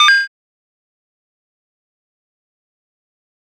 WaterSFX.wav